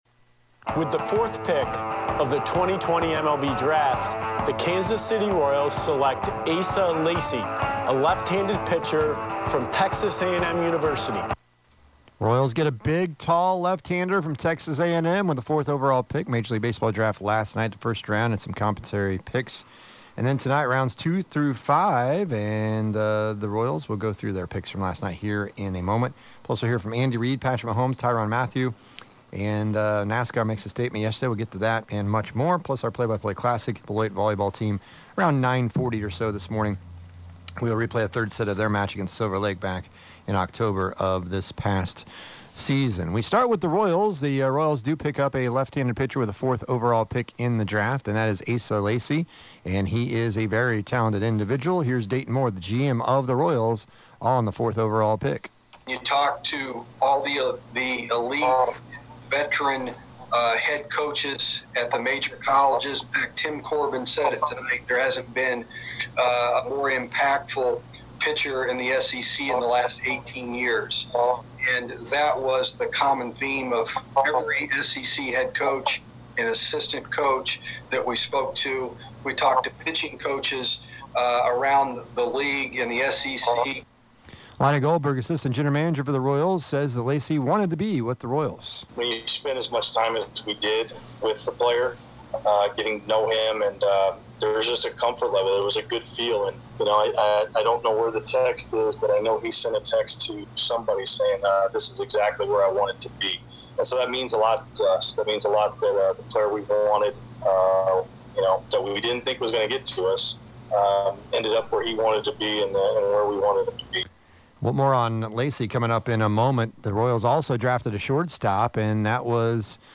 Andy Reid, Patrick Mahomes, Tyrann Mathieu speak
We replay the 3rd set of the 2019 3A State Semifinal Beloit vs Silver Lake